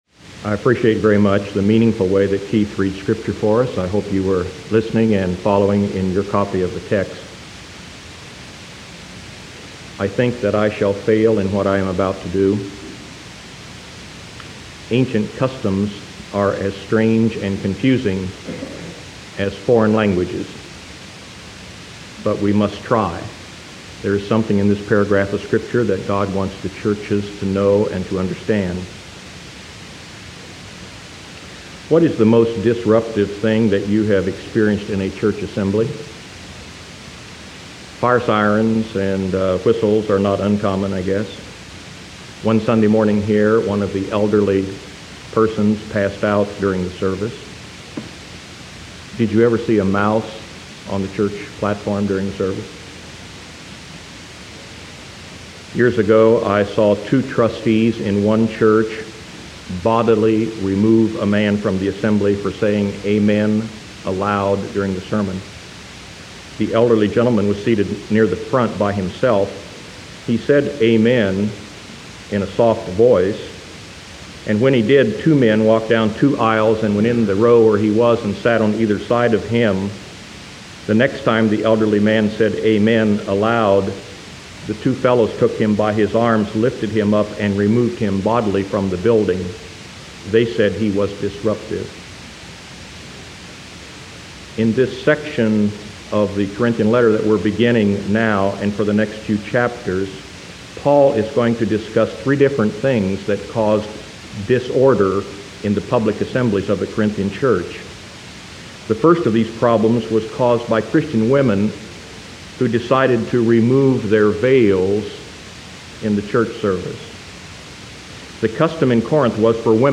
1 Corinthians 11:1-16 Preacher